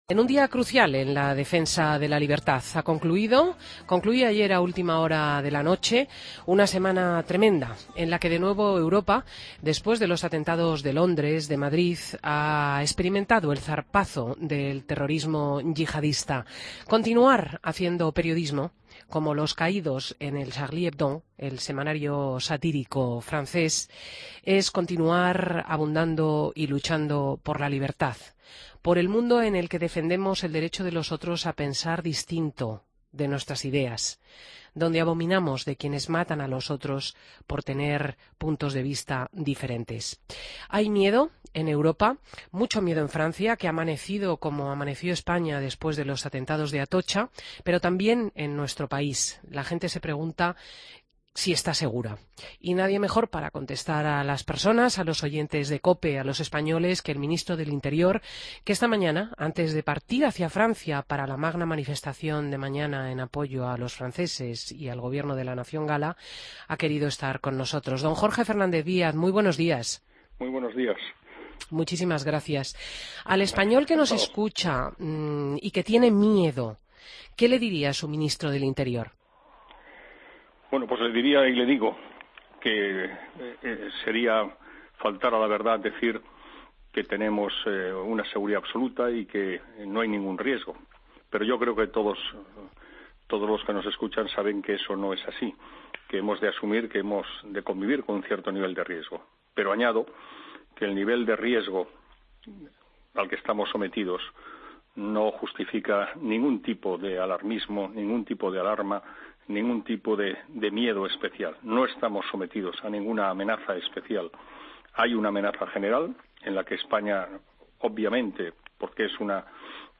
Entrevista a Jorge Fernández-Díaz en Fin de Semana COPE